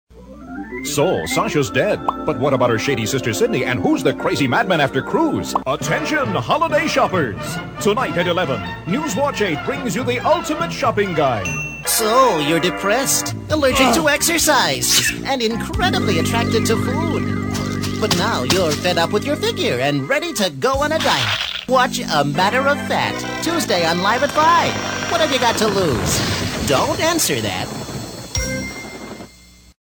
Character Voice Overs / Character Voiceover Actor Demos
Male voice over talent